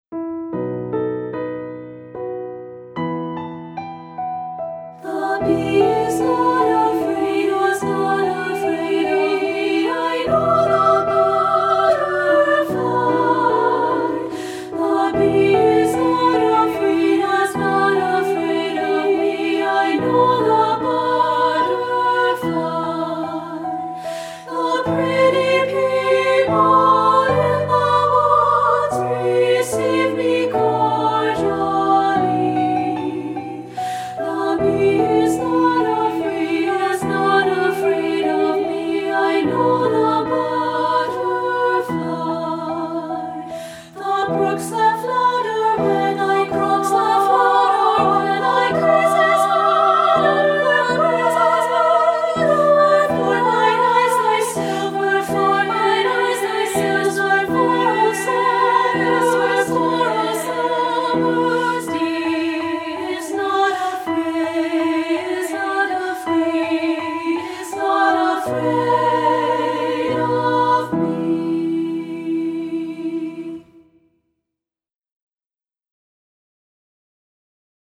Voicing: SA a cappella